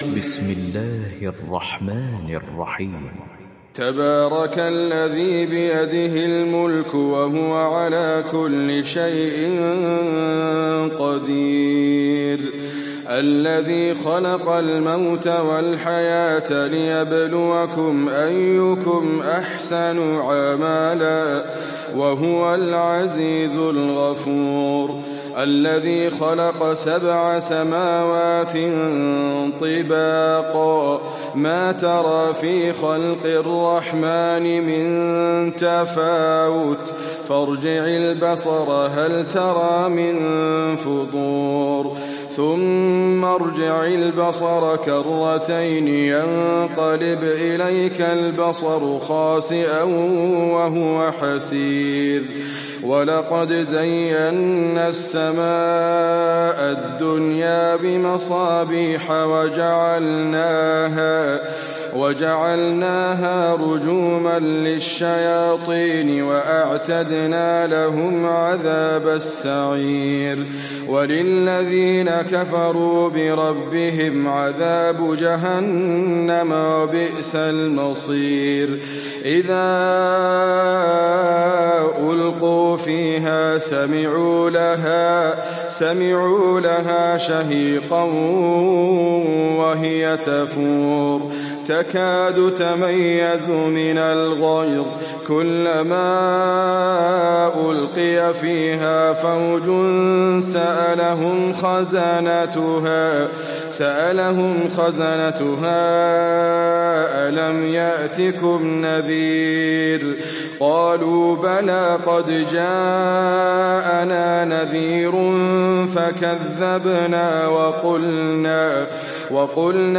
تحميل سورة الملك mp3 بصوت إدريس أبكر برواية حفص عن عاصم, تحميل استماع القرآن الكريم على الجوال mp3 كاملا بروابط مباشرة وسريعة